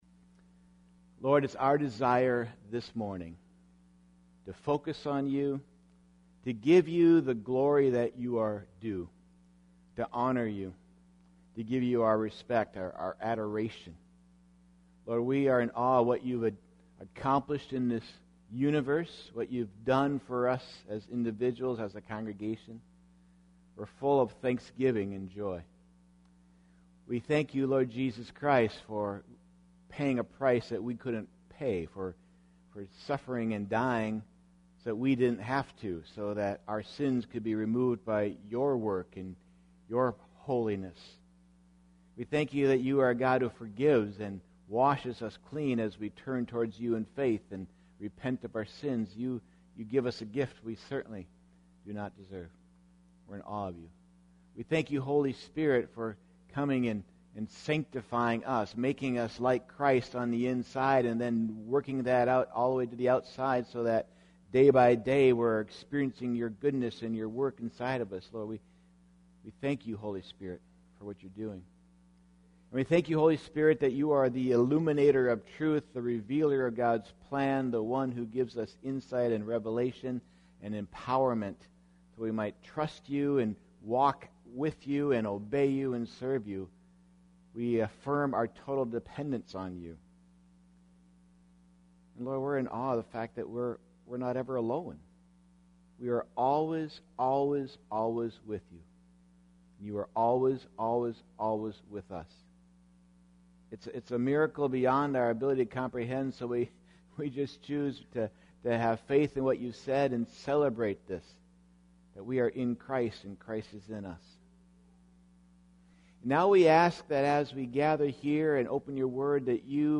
Series: Sunday Service Topic: Obedience